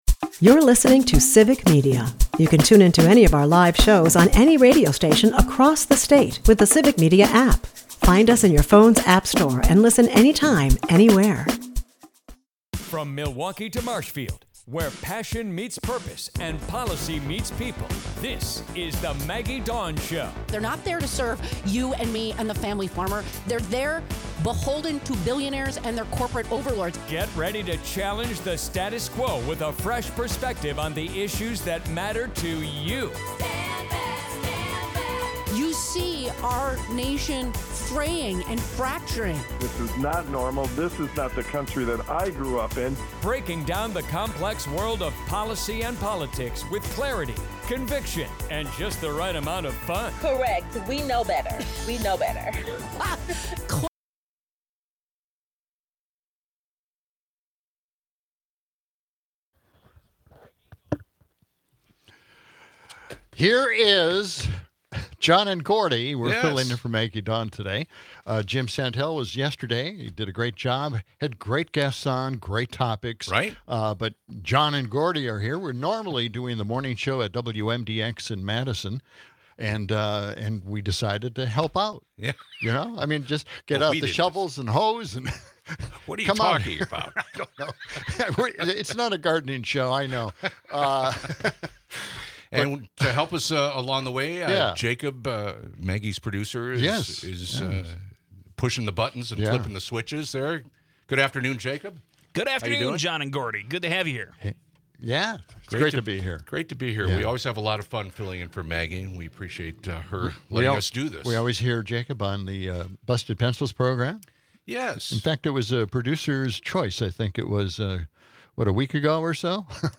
reporting live from Packers training camp and talking Green Bay's upcoming season and the Brewers' winning streak.